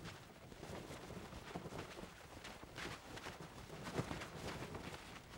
cloth_sail13.R.wav